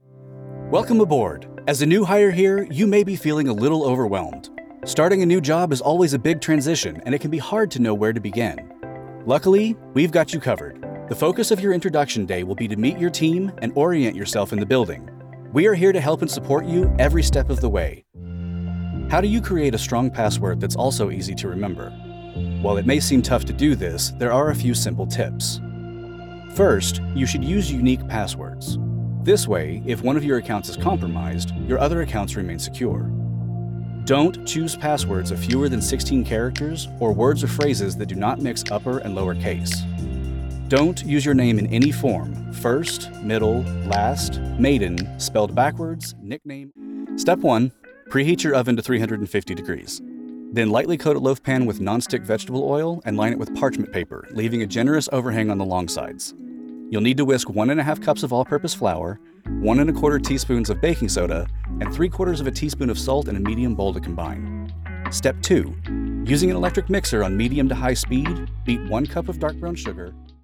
Professional male voiceover artist for your next project!
Explainer Demo
My broadcast-quality home studio setup includes:
• Microphone: AKG Pro C214 XLR
• Sound Treatment: 2-inch acoustic foam panels and bass traps